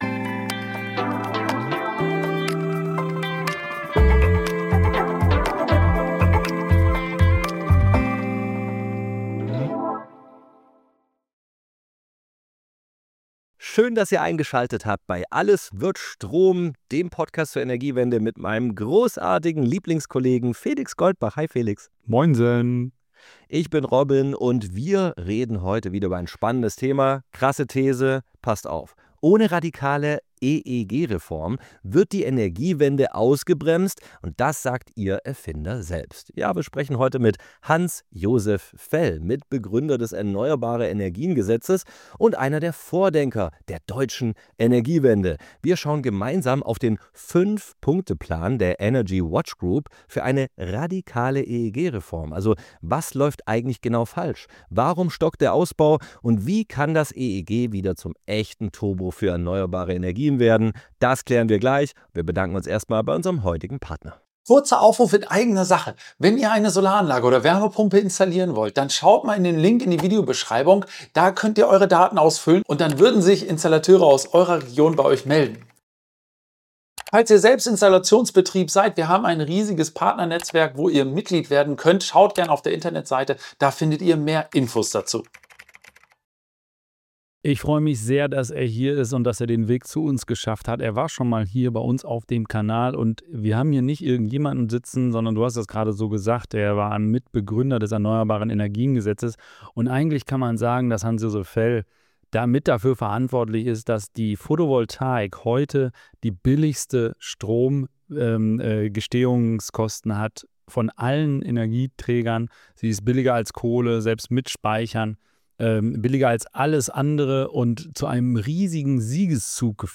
Ein Pflichtgespräch für alle, die verstehen wollen, warum die Energiewende stockt – und wie sie wieder Fahrt aufnimmt.